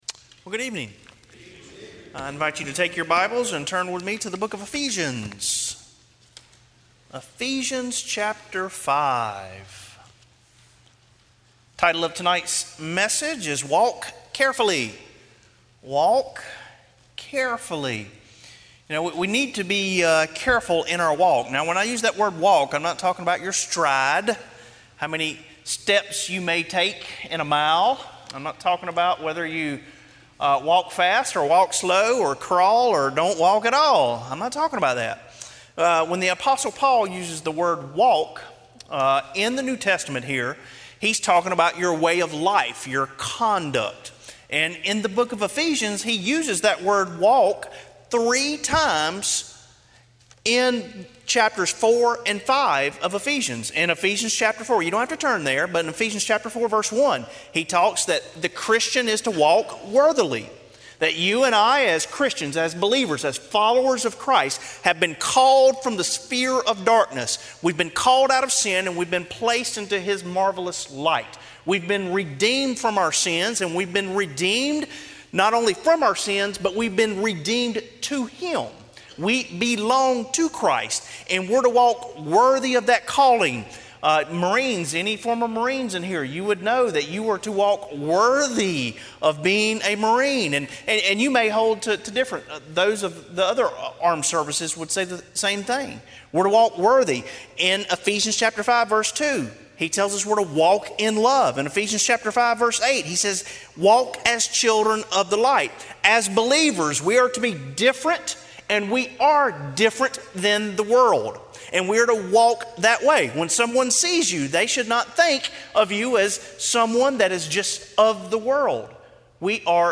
Sermon Audios/Videos - Tar Landing Baptist Church
Evening WorshipEphesians 5:15-20